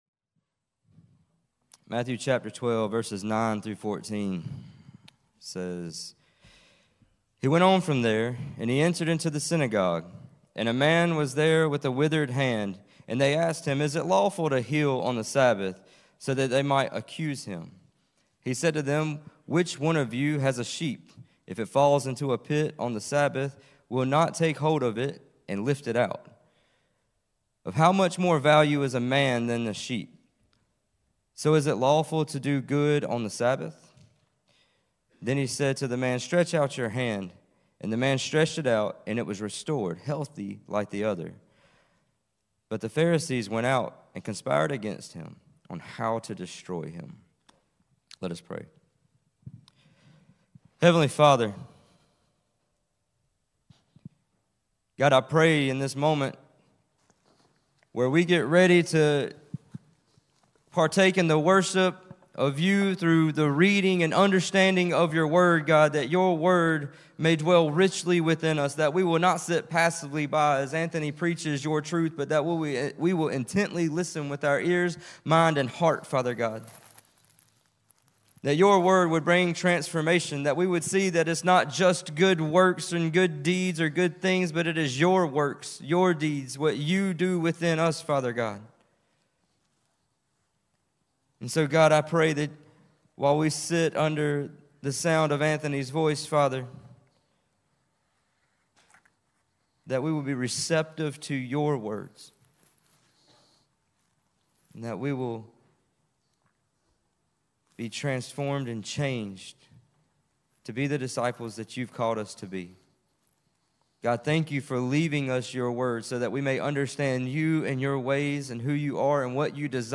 Sermon-1-5-24.mp3